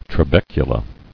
[tra·bec·u·la]